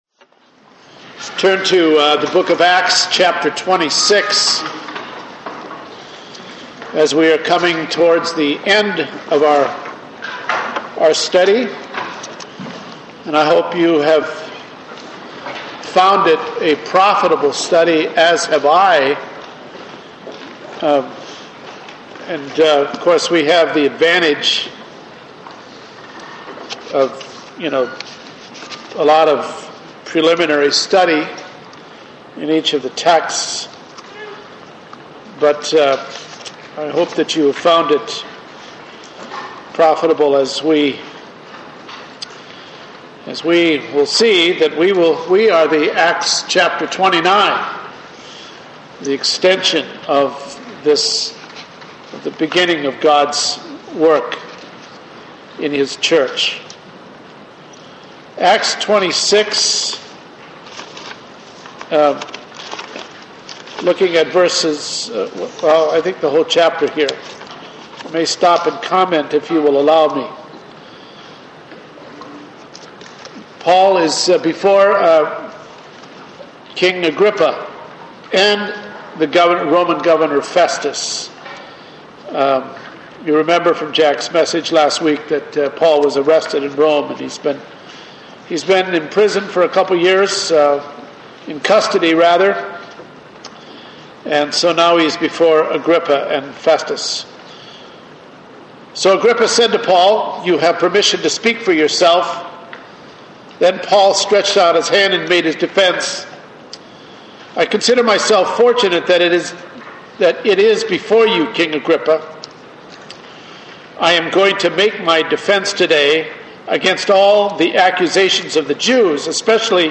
Passage: Acts 26:1-32 Service Type: Sunday Morning